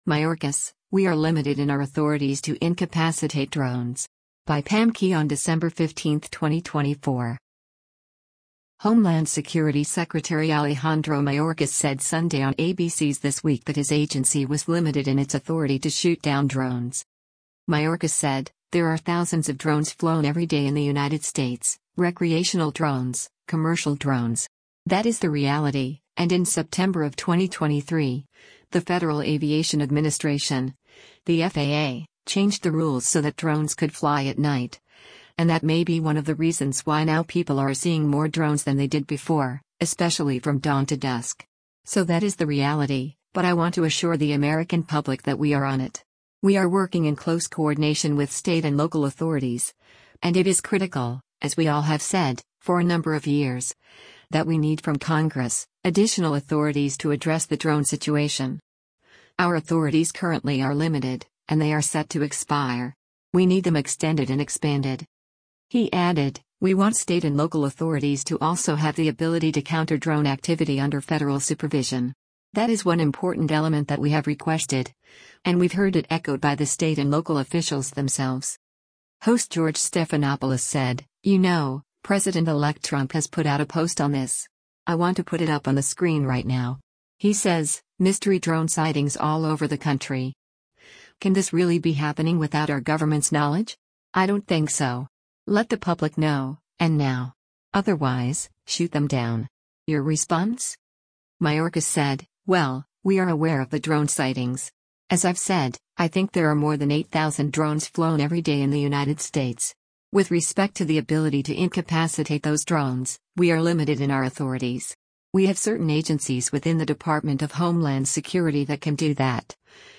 Homeland Security Secretary Alejandro Mayorkas said Sunday on ABC’s “This Week” that his agency was “limited” in its authority to shoot down drones.